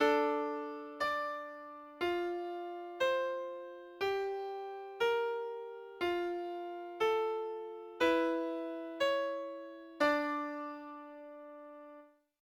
No exemplo a seguir, em duas partes, o cantus firmus é a parte do baixo.
Cada um está no modo Dórico.
Pequeno exemplo de um contraponto de "Quarta Espécie"
Contraponto de quarta espécie